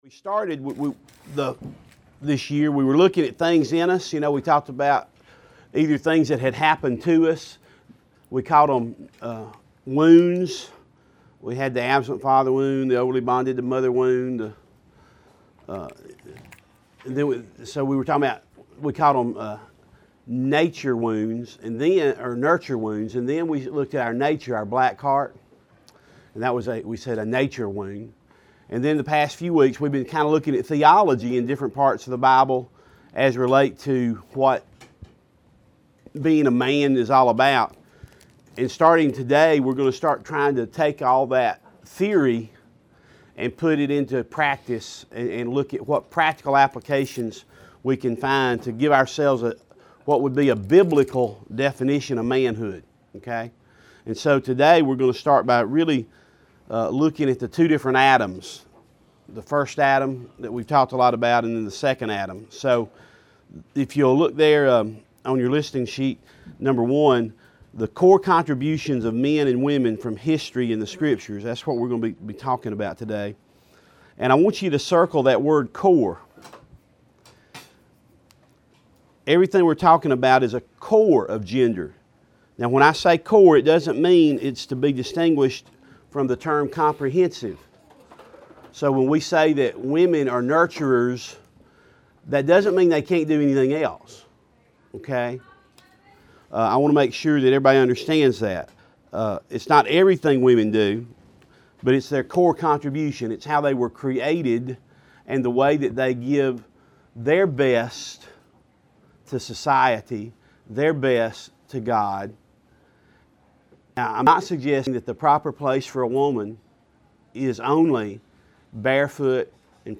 Each Tuesday morning a group of men sit down together to hear what the Bible has to say about authentic manhood.